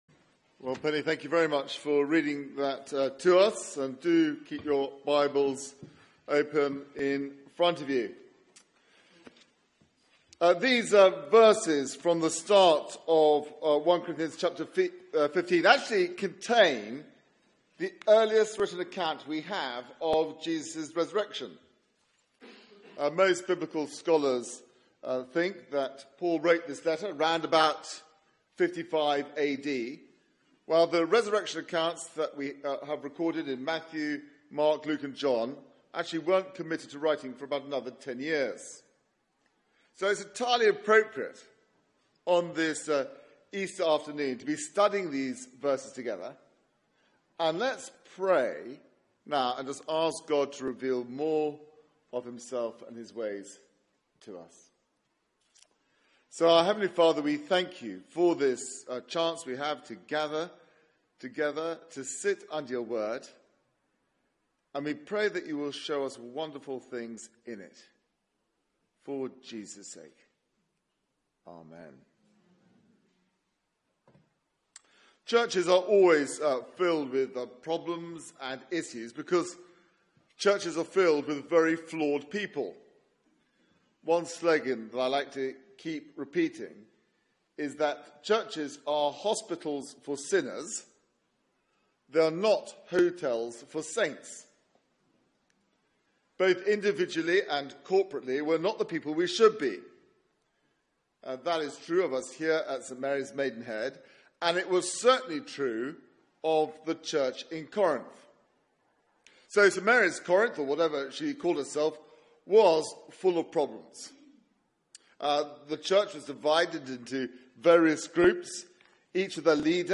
Media for 4pm Service on Sun 16th Apr 2017 16:00 Speaker
Easter Theme: Of first importance Sermon Search the media library There are recordings here going back several years.